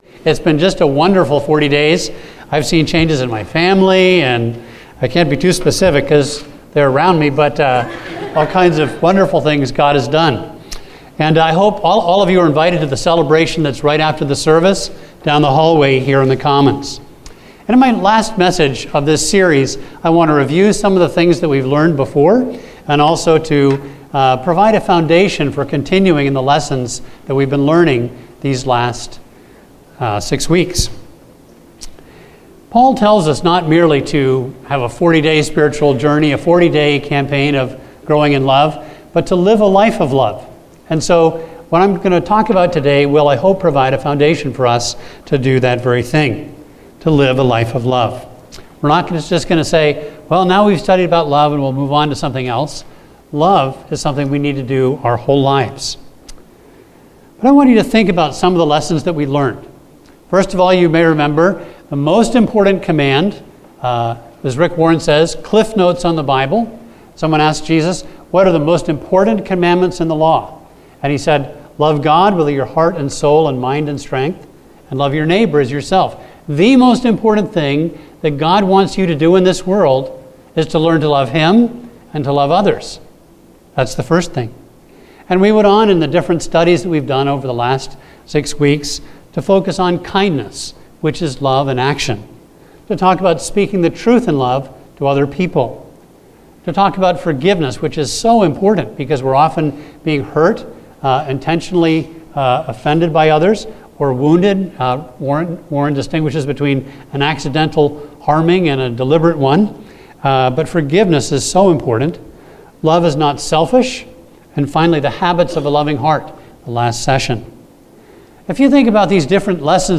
A message from the series "40 Days of Love."